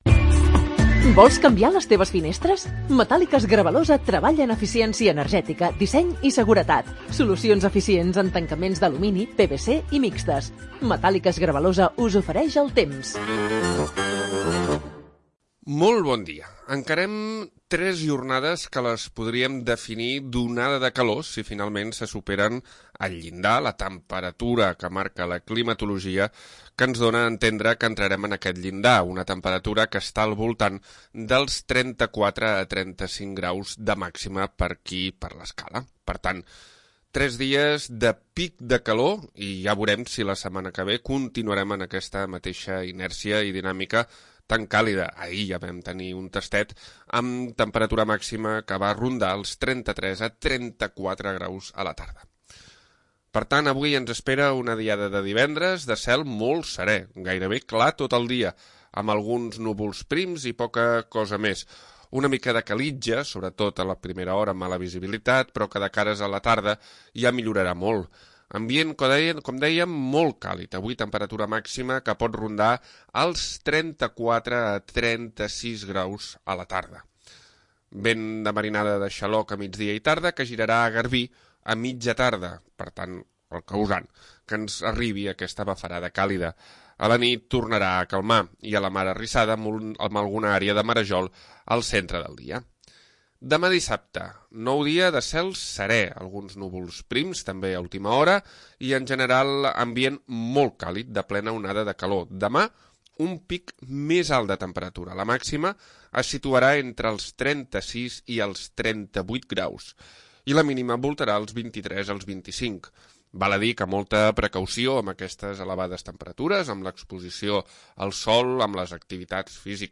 Previsió meteorològica 20 de juny de 2025